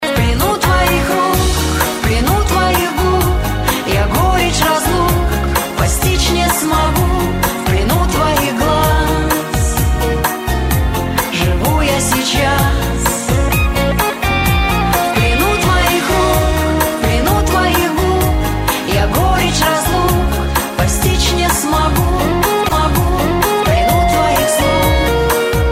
из Шансон